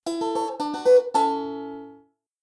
Banjo